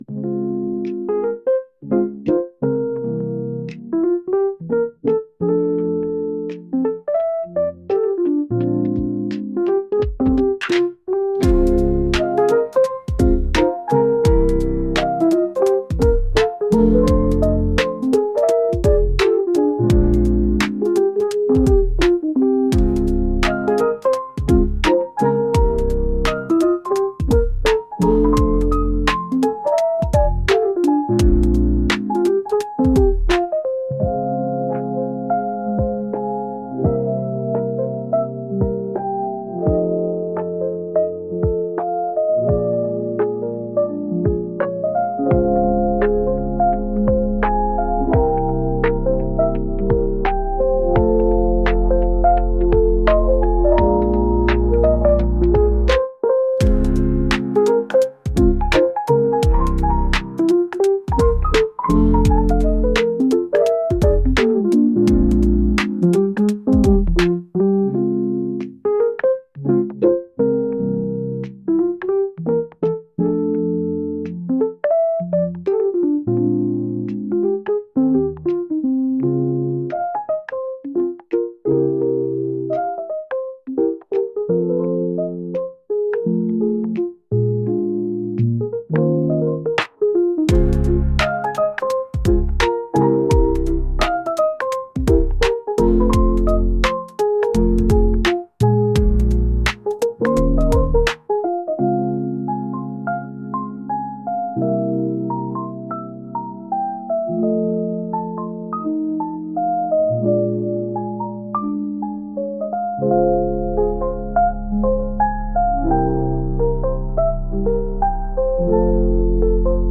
眠たくなりそうなlo-fi曲です。